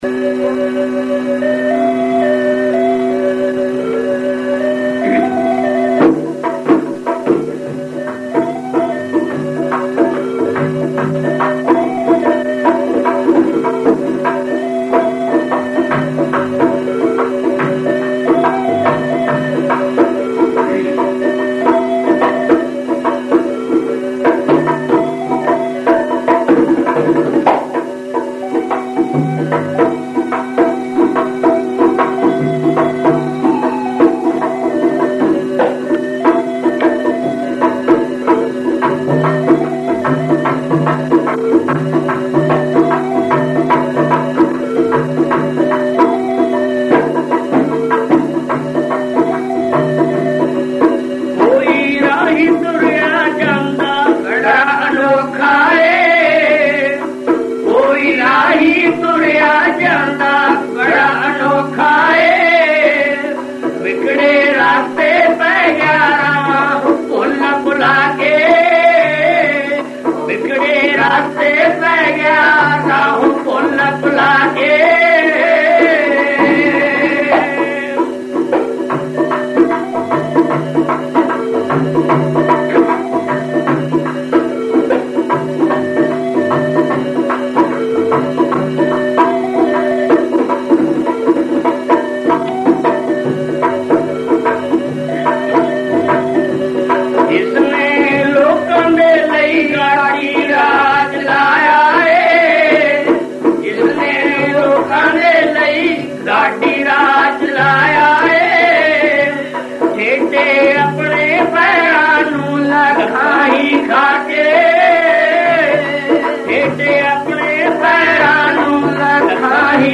audio/Dhadi Vaaran 01 Maina Banthi Gopi Chand Tai Akhdi 02 Baitha Tambu Vich Guru Dashmesh Ji 03 Koi Rahi Tureya Janda Bara Anokha 04 Cha Jena Nu Shaheediya Paunda 05 Laya Aakhri Darbar Dasvan Patshsh